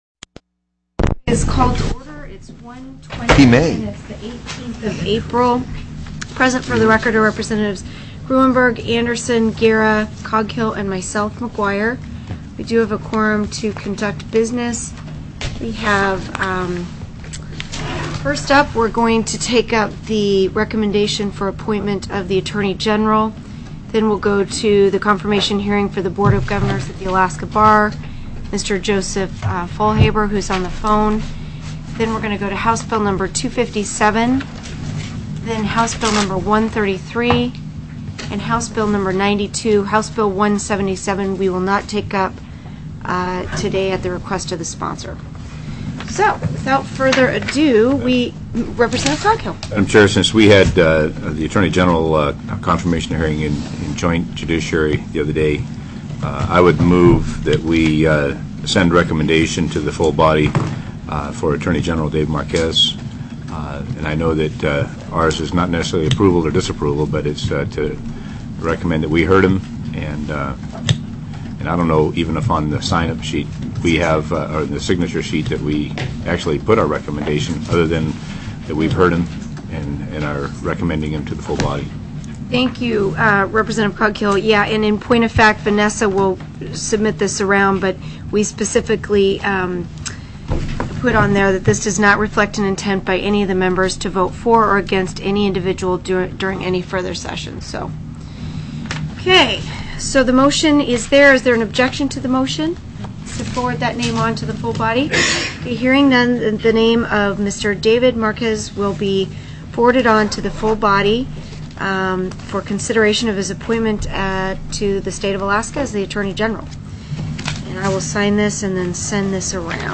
04/18/2005 01:00 PM House JUDICIARY